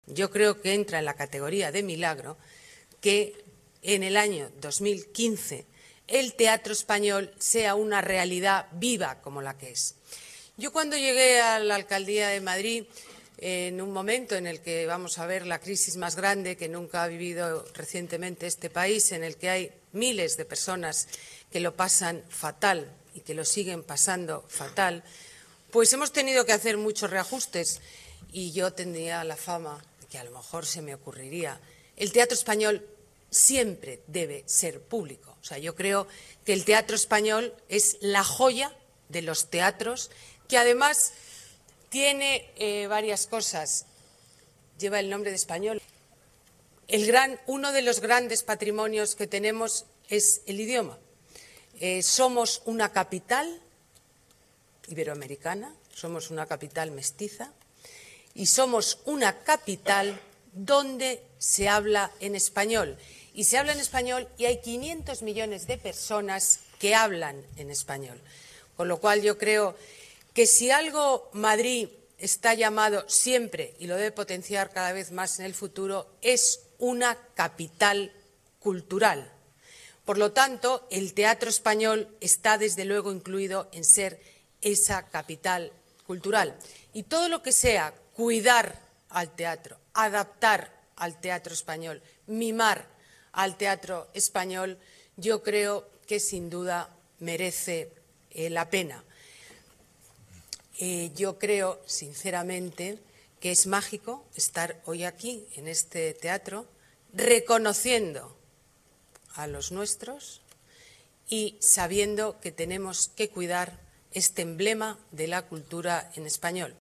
Nueva ventana:Declaraciones Ana Botella: Teatro Español